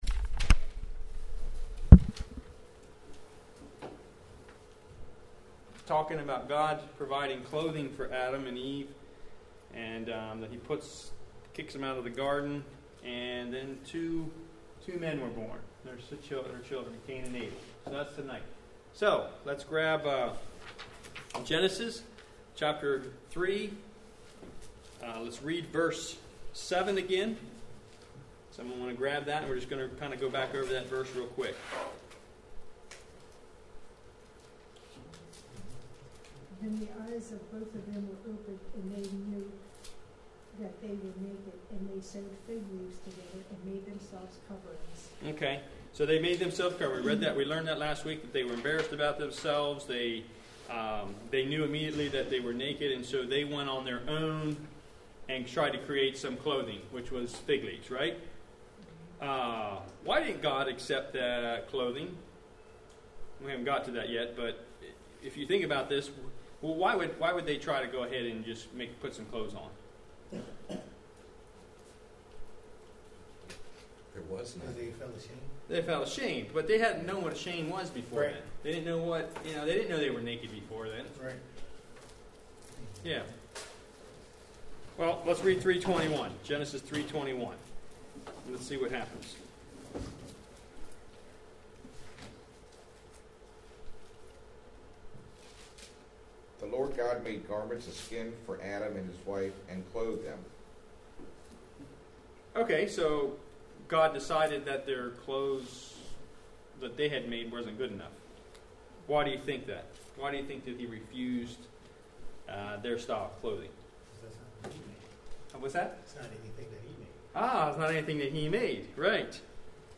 Lesson 11